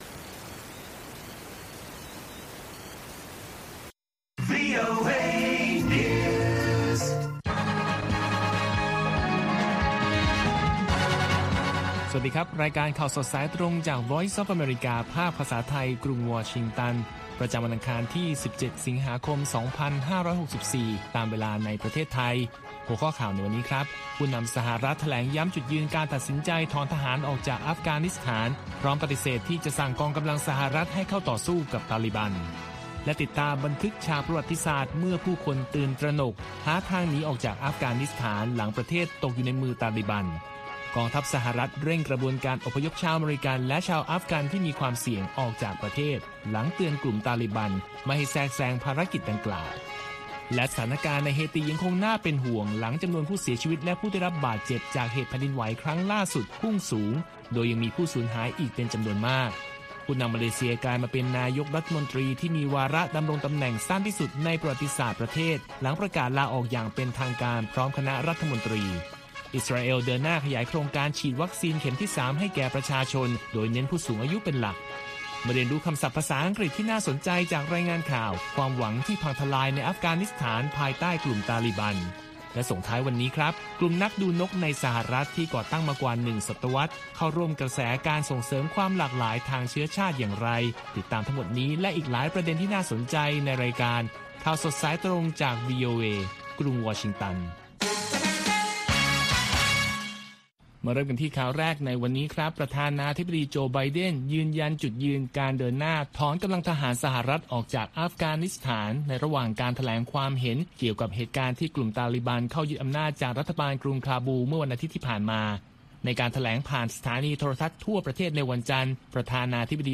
ข่าวสดสายตรงจากวีโอเอ ภาคภาษาไทย ประจำวันอังคารที่ 17 สิงหาคม 2564 ตามเวลาประเทศไทย